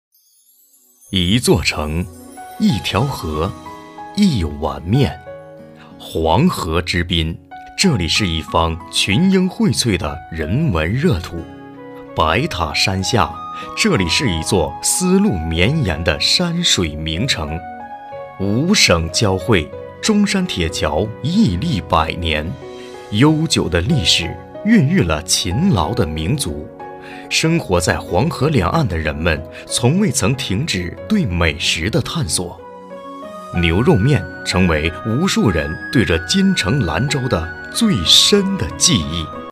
舌尖-男51.mp3